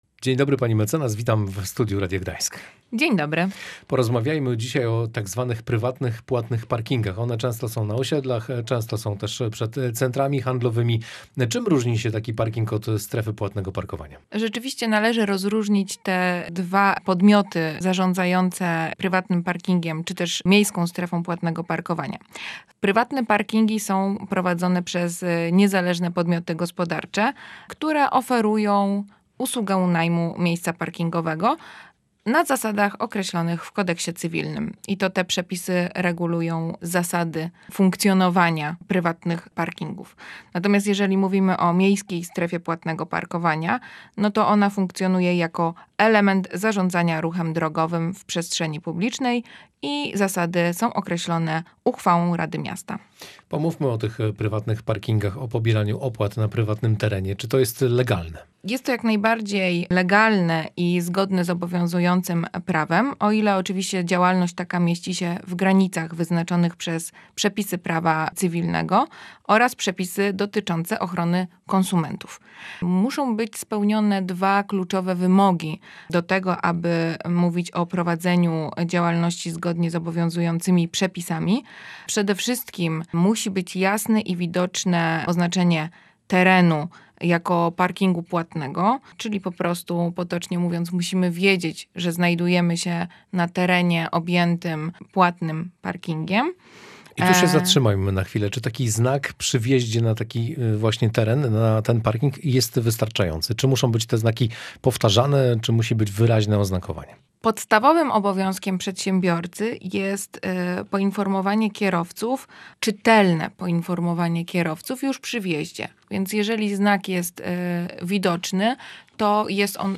Wszystko o prywatnych płatnych parkingach. Rozmawiamy z adwokat